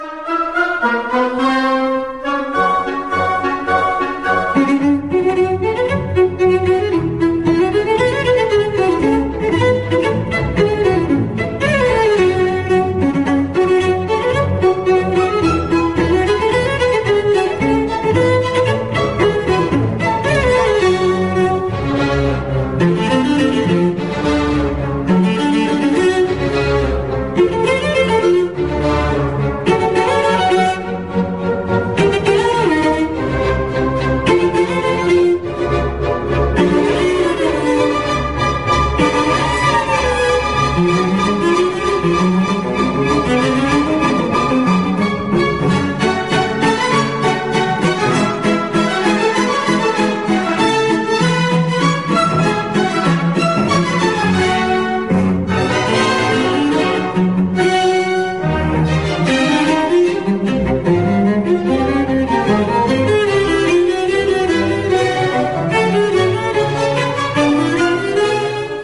Scottish Fantasy for Cello and Orchestra op. 71 (rec.
cello